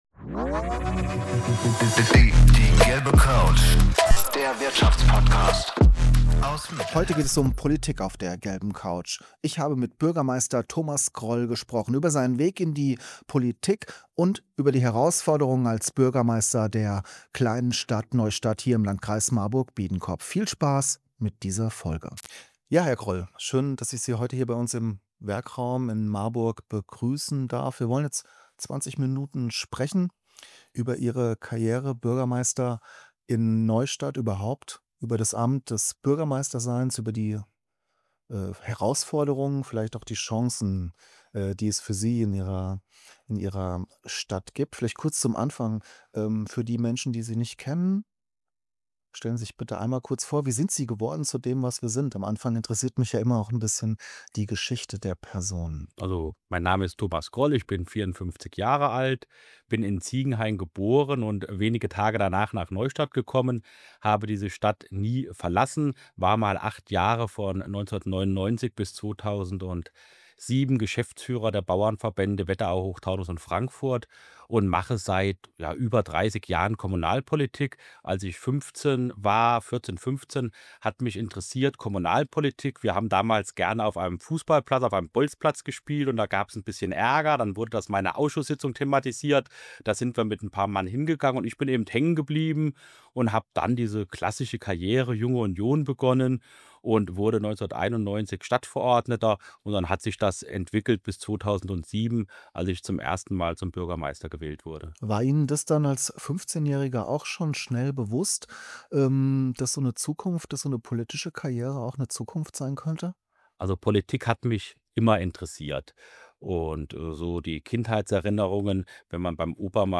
In dieser Folge spreche ich mit Thomas Groll, Bürgermeister von Neustadt im Landkreis Marburg-Biedenkopf. Wir reden über seine persönliche Geschichte, die Herausforderungen als Wohnstadt ohne große Gewerbesteuereinnahmen und darüber, wie sich Neustadt als lebenswerter Wohnort positioniert. Themen sind außerdem kommunale Sozialpolitik, Ehrenamt und der Spagat zwischen Tradition und Zukunft.